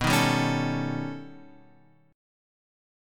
B+9 chord {7 6 5 6 x 5} chord